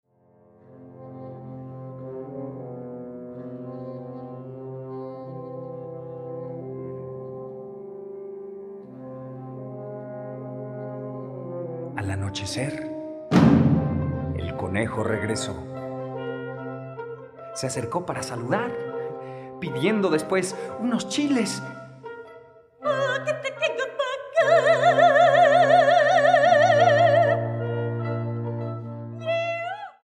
ópera infantil